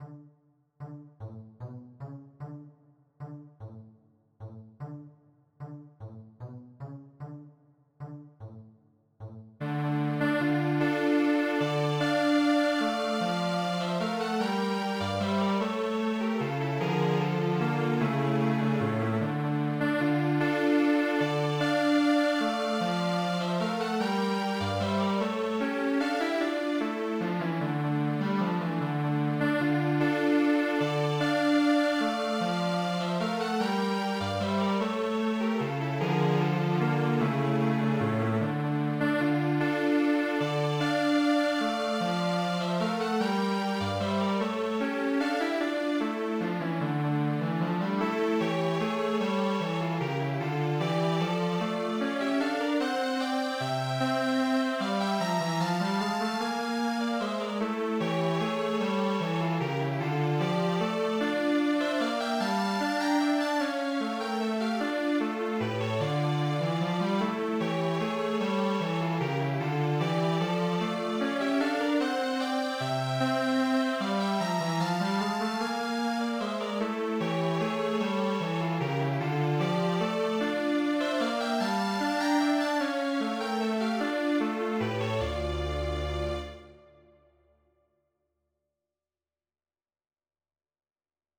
Valse   - Page de garde